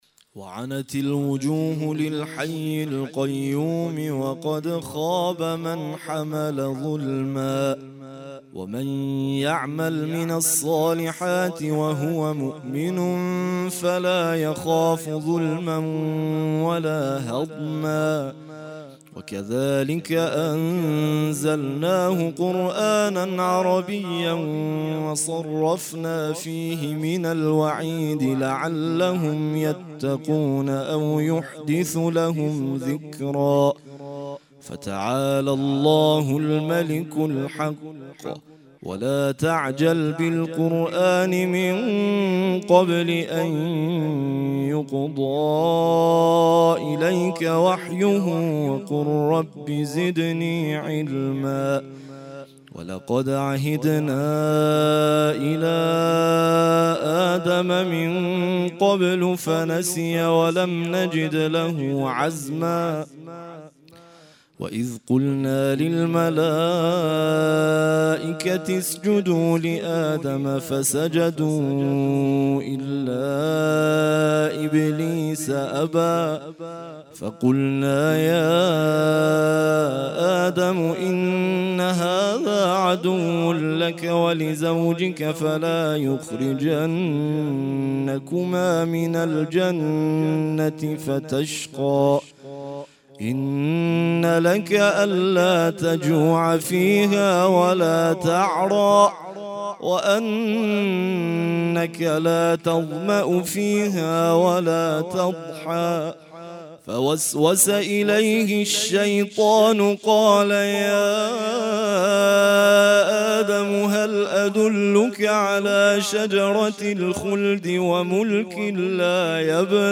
ترتیل خوانی جزء ۱۶ قرآن کریم - سال ۱۴۰۳